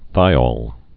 (thīôl, -ōl, -ŏl)